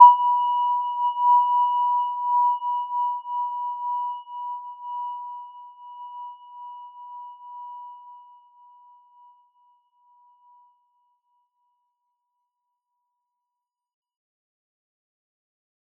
Gentle-Metallic-1-B5-p.wav